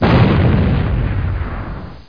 explosin.mp3